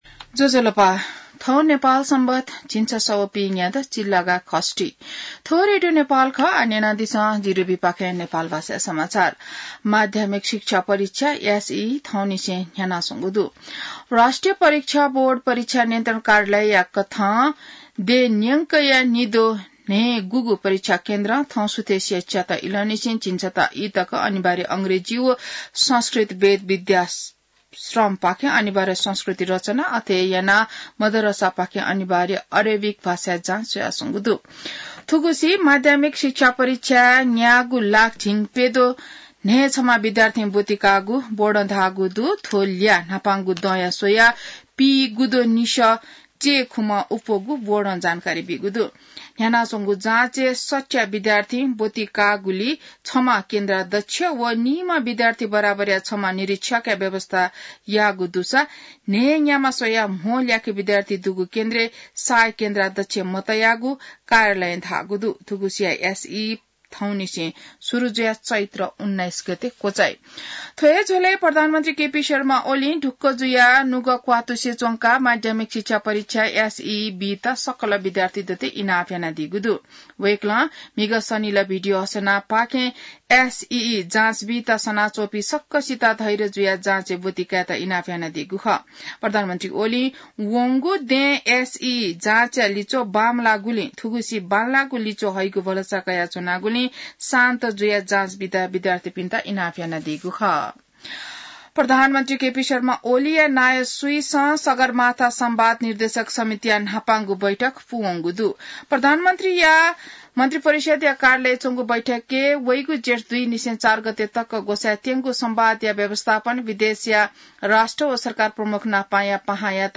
नेपाल भाषामा समाचार : ७ चैत , २०८१